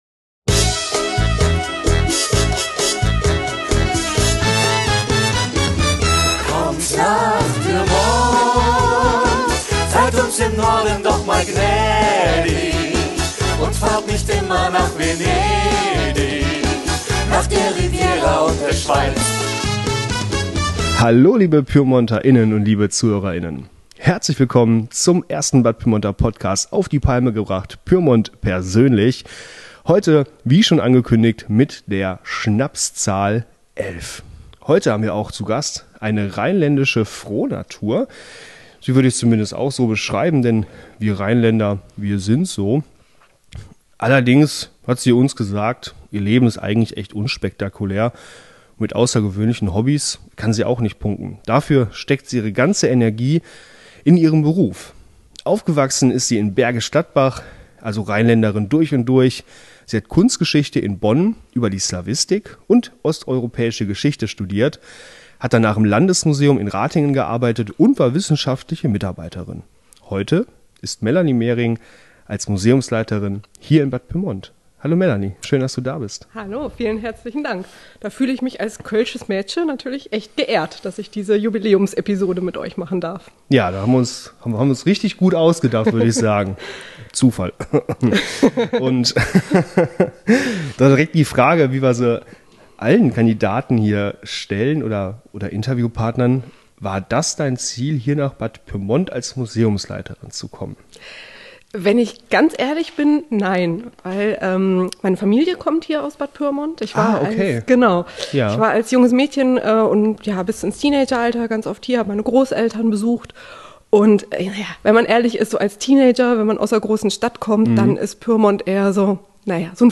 In unserer 11. Episode des Bad Pyrmonter Podcast treffen zwei Rheinländer aufeinander - und das spürt man. Ja, es wird kurz über Karneval gesprochen und auch über die gemeinsame Heimat und Reiseziele - nicht zuletzt deswegen entwickelt die Episode eine tolle Dynamik.